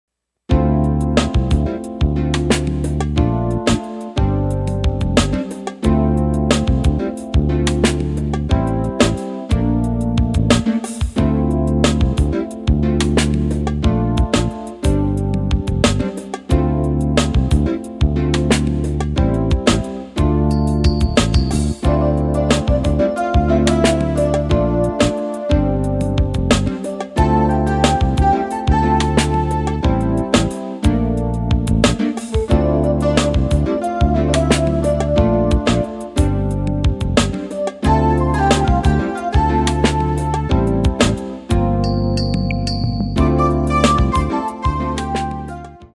Jazz-pop-fusion.